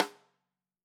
Index of /musicradar/Snares/Sonor Force 3000
CYCdh_SonRim-04.wav